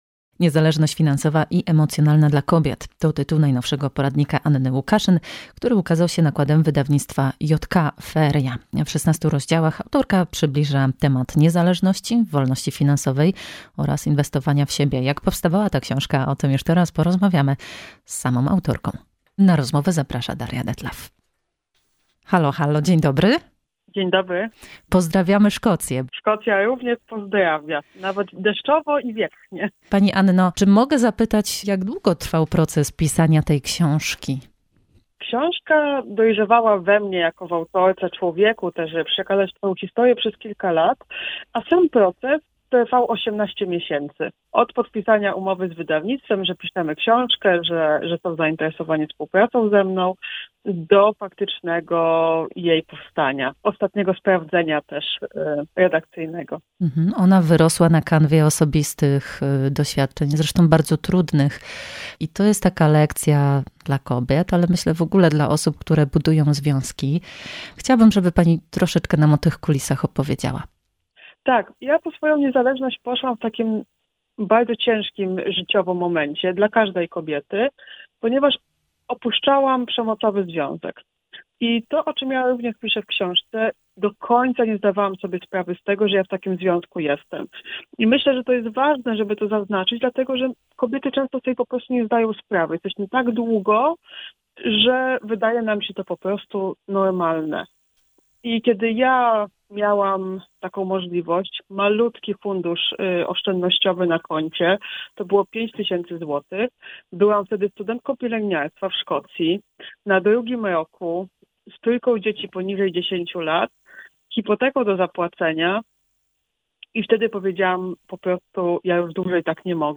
Emisja wywiadu we wtorek 30 stycznia po godz. 16:10.